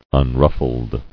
[un·ruf·fled]